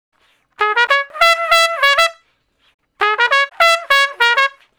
087 Trump Straight (Db) 12.wav